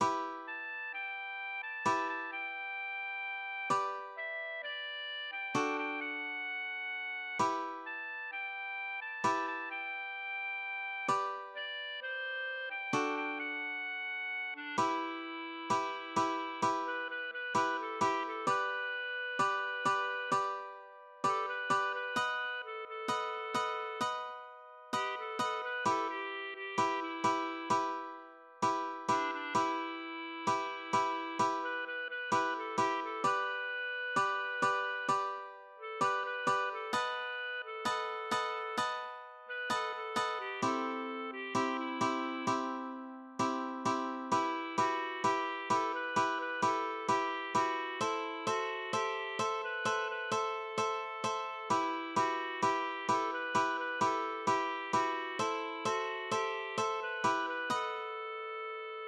acoustic guitar (nylon)